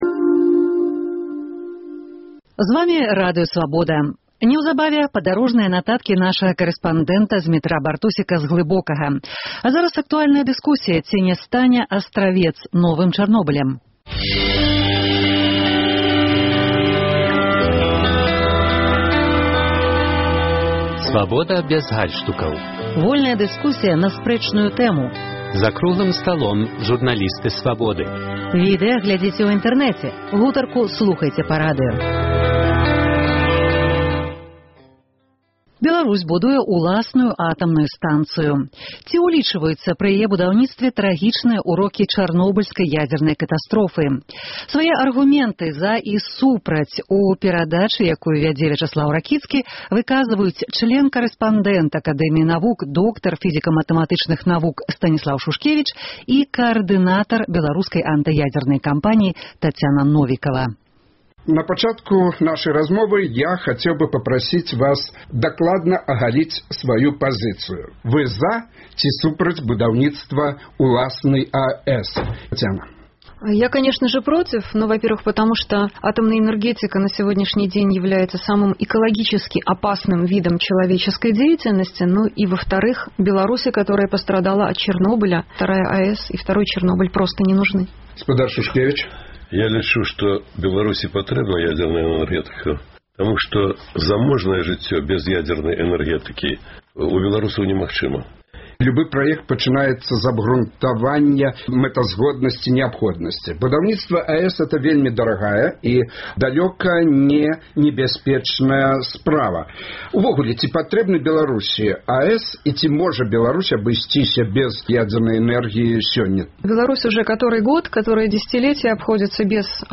Свае аргумэнты «за» і «супраць» будаўніцтва АЭС выказалі член-карэспандэнт Нацыянальнай акадэміі навук, доктар фізыка-матэматычных навук Станіслаў Шушкевіч